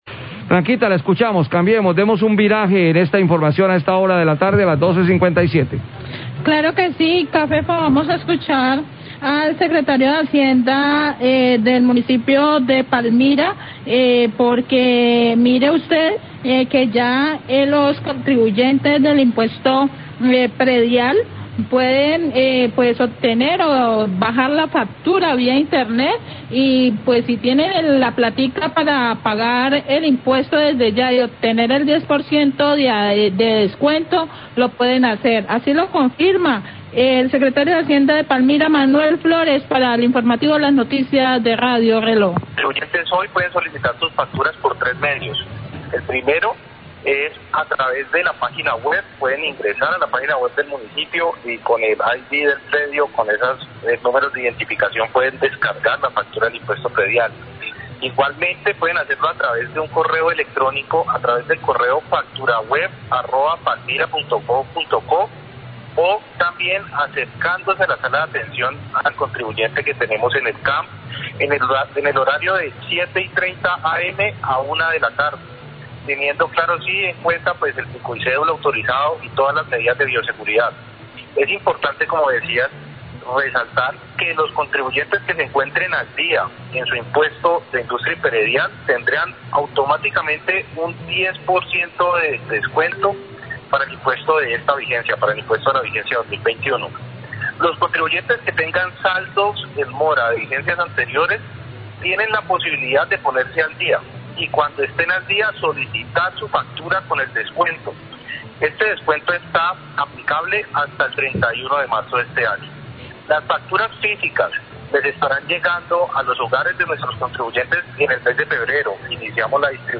Srio Hacienda de Palmira habla sobre el pago del impuesto predial
Radio